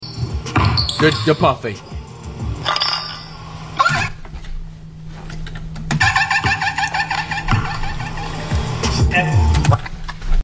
Flights dolphin laugh is top sound effects free download
Flights dolphin laugh is top tier 🤣